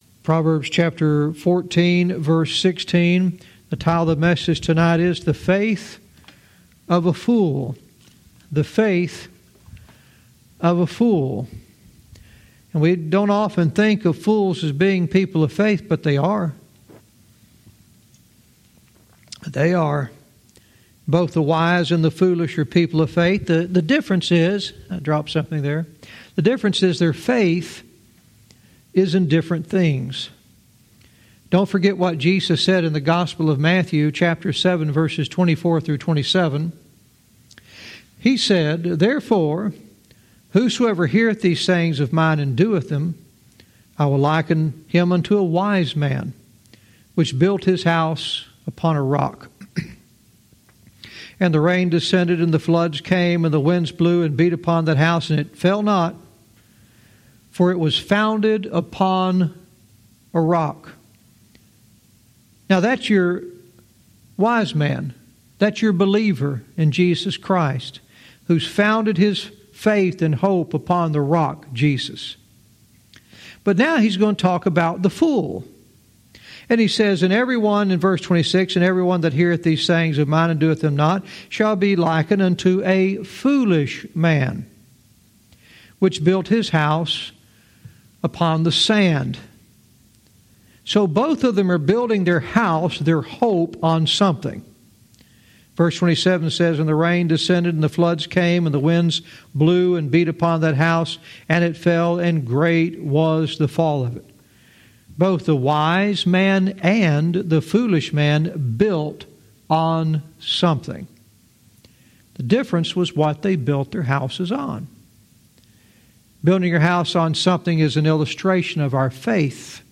Verse by verse teaching - Proverbs 14:16 "The Faith of a Fool"